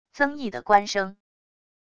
曾毅的官声wav音频